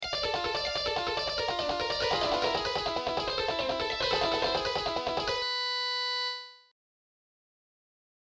Sweep+Arpegio.mp3